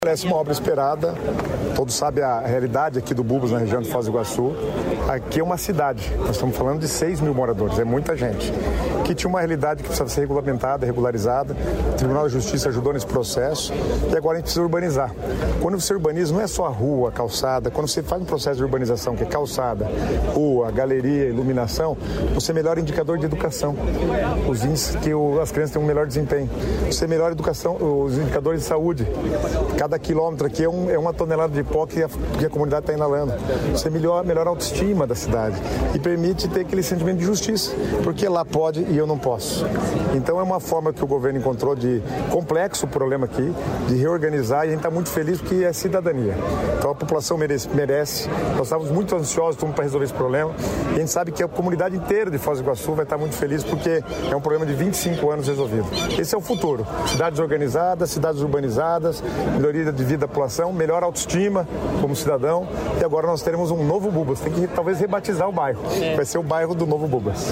Sonora do secretário estadual das Cidades, Guto Silva, sobre as obras de urbanização no Bubas, em Foz do Iguaçu